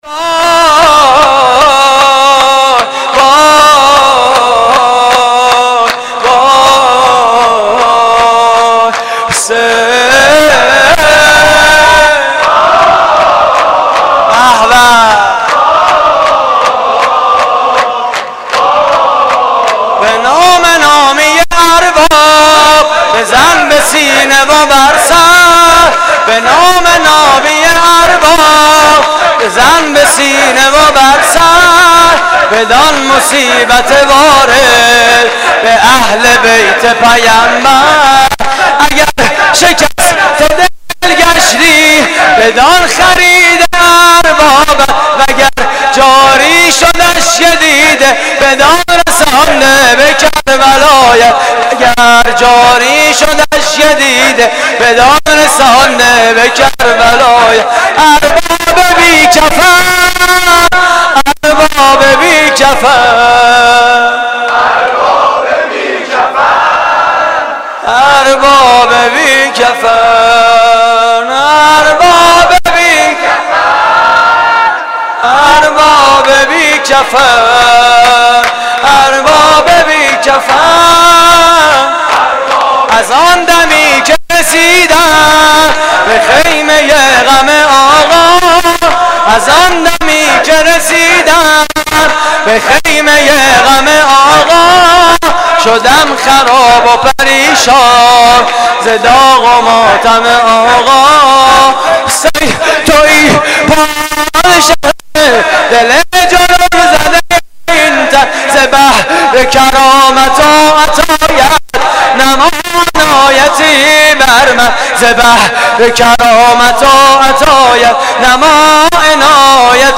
شور: به نام نامی ارباب
مراسم عزاداری شب پنجم محرم 1432